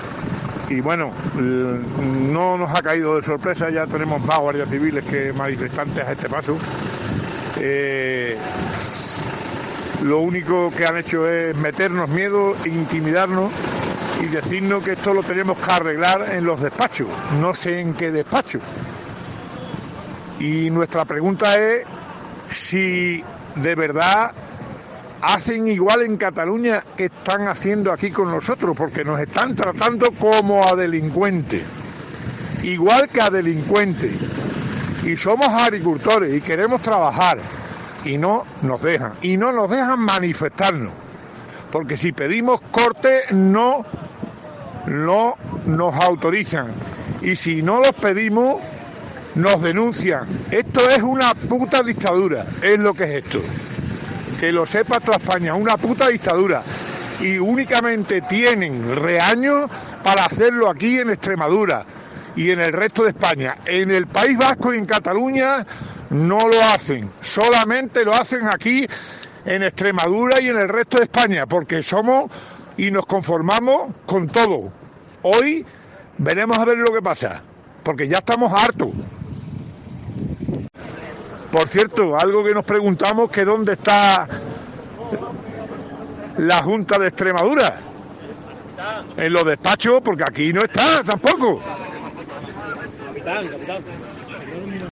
Un agricultor extremeño estalla ante las amenazas de multa: "Esto es una dictadura..."
Están muy enfadados.
Se muestra enfadado e indignado: "Nos están tratando como delincuentes, solo se atreven con Extremadura y el resto de España, con País Vasco y Cataluña no, porque aquí aguantamos con todo...".